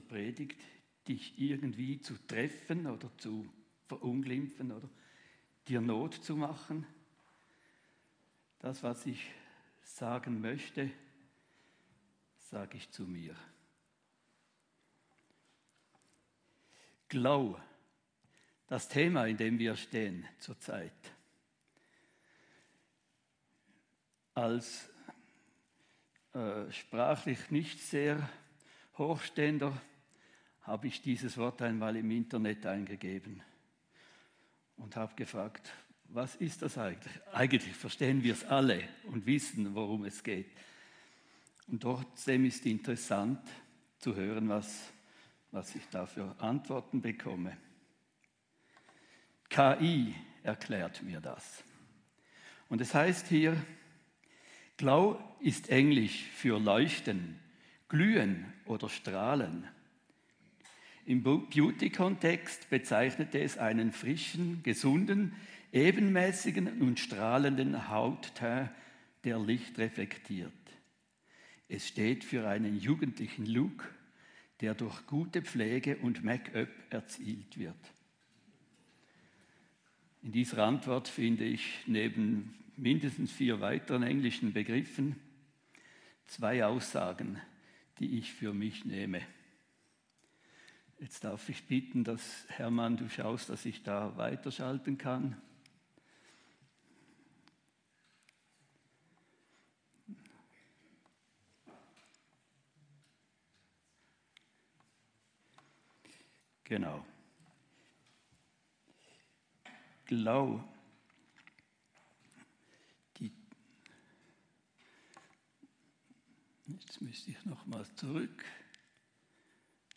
Predigt-22.2.26.mp3